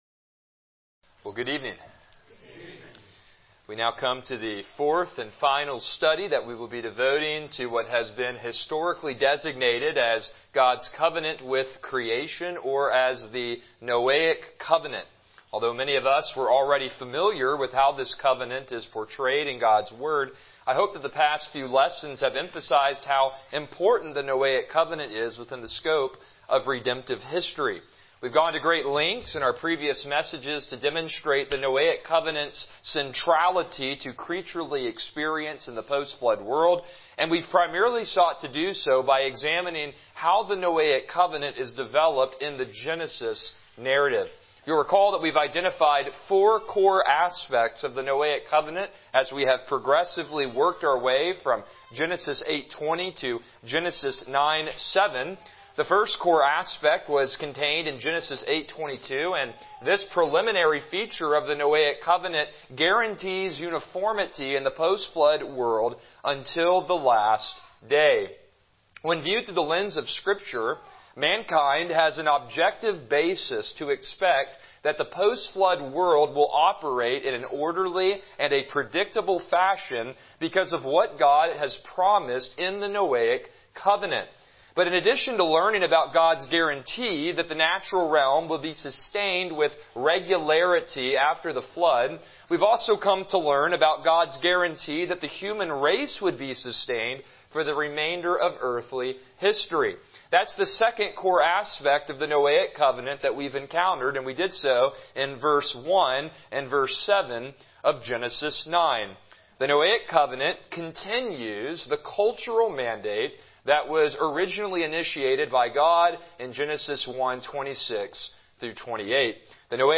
Passage: Genesis 9:8-17 Service Type: Evening Worship